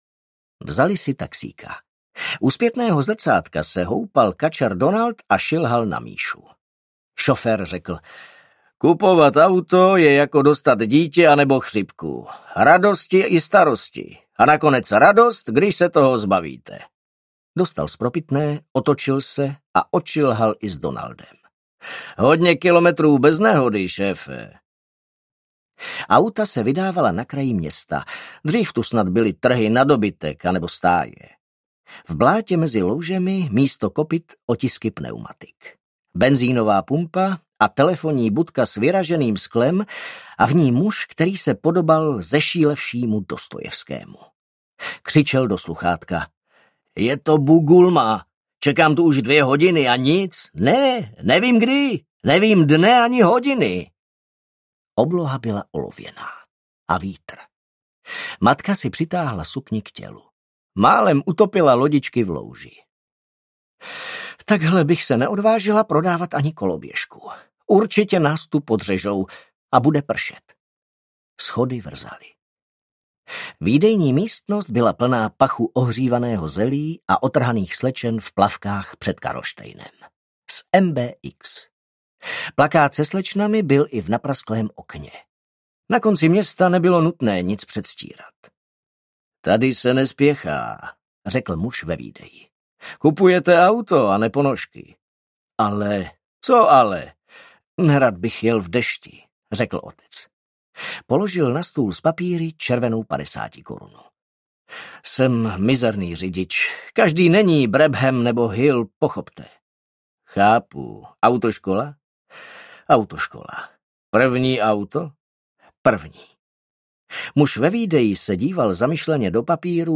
Červená kůlna audiokniha
Ukázka z knihy